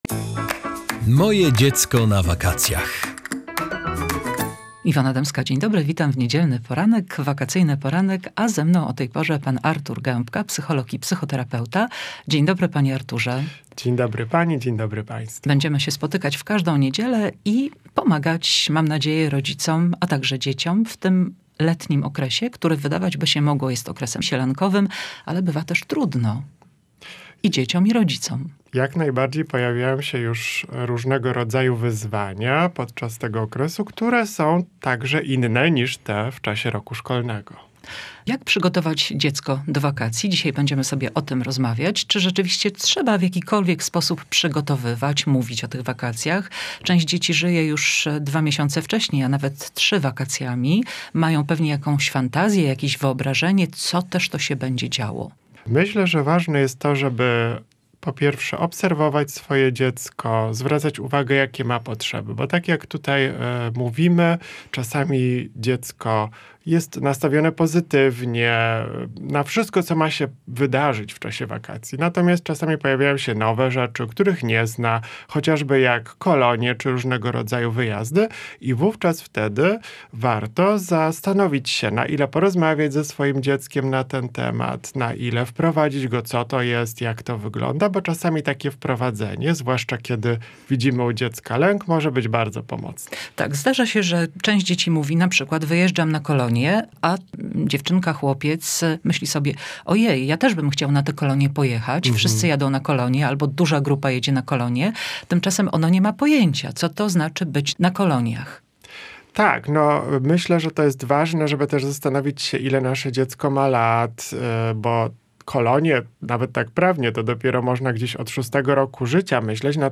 W pierwszym odcinku rozmawialiśmy o tym jak przygotować dziecko do wakacji.